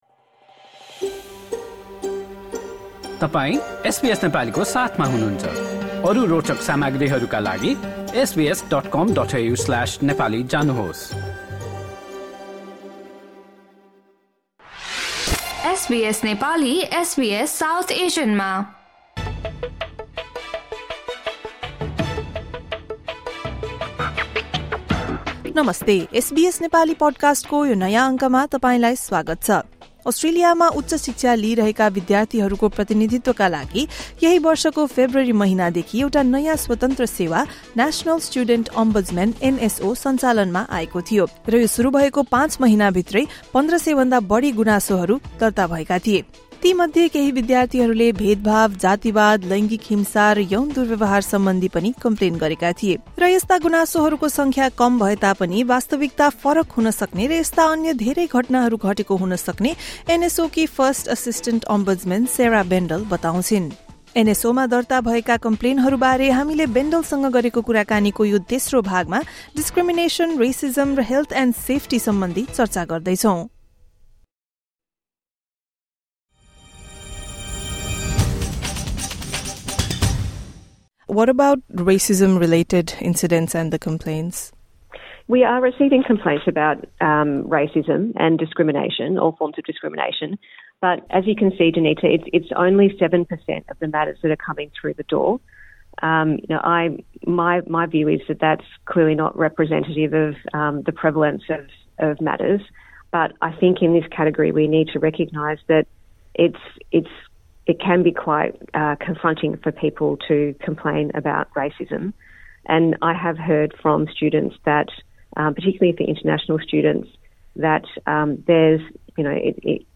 यही वर्षको फेब्रुअरीदेखि सञ्चालनमा आएको एनएसओमा जुनसम्म विभिन्न विषयमा कुल १,४७१ वटा गुनासोहरू दर्ता भएको उनको भनाइ छ। यही सन्दर्भमा एसबीएस नेपालीले बेन्डलसँग गरेको कुराकानीको पहिलो भाग सुन्नुहोस्।